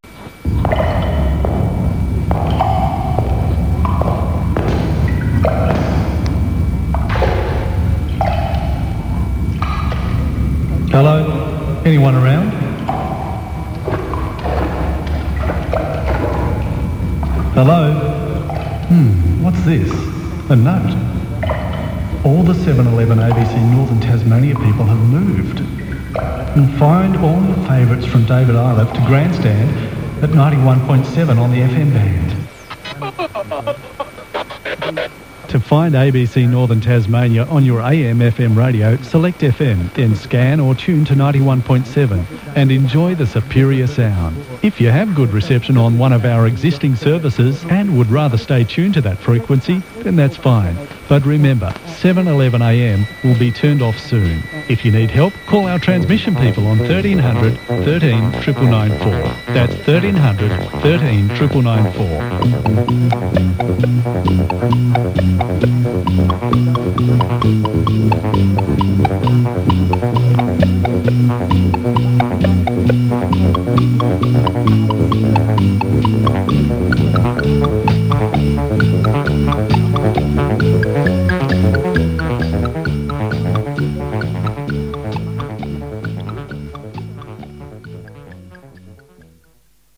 ABC North Tas used that song for the loop message on 711 AM when they converted to FM:
ABCNorthernTasmania_AMtoFM.wav